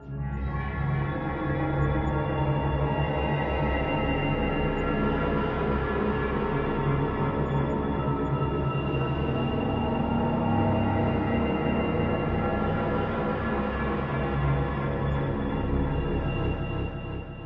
大气层迷你包" ab神秘的大气层
Tag: 实验 frea KY 声景 环境 无人驾驶飞机 恐怖 不断发展 声音 大气压